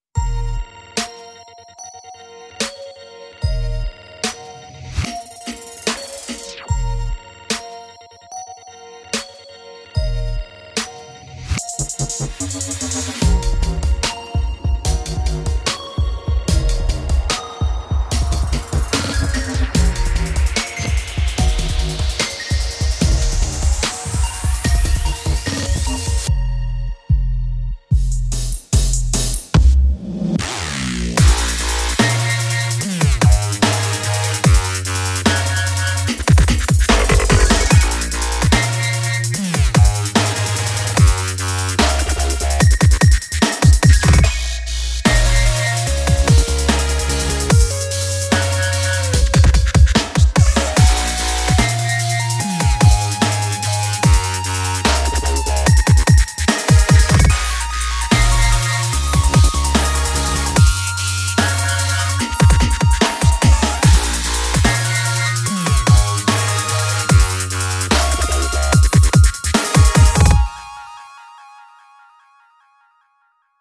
该包中包含重击的Dubstep样本，从沉重的踢腿和令人讨厌的小军鼓到暴力的FX潘德大旋律……如果您正在寻找重量级的Dubstep声音，此背包中就充满了！
–以48kHz / 24bit录制
–非常适合所有风格的Dubstep音乐，包括Classic Dubstep，Filthstep，Brostep和所有其他疯狂的子类型！